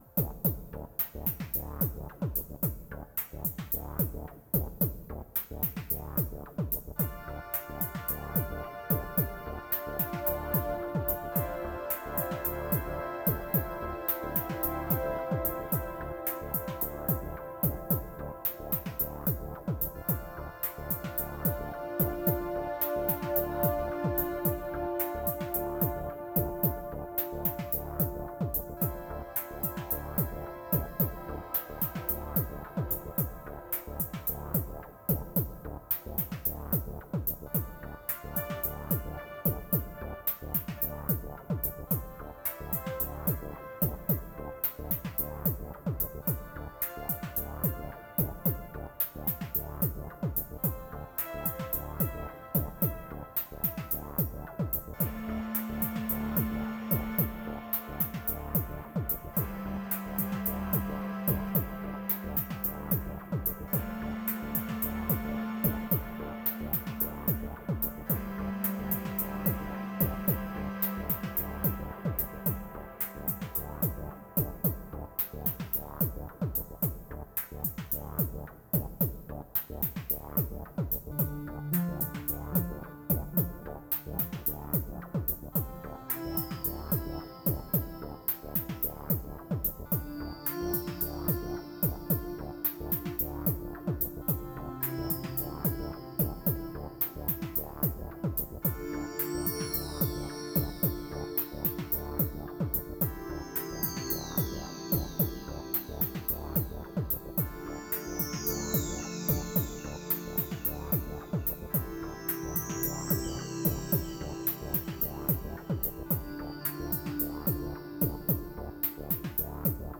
Tempo: 60 bpm / Datum: 16.09.2018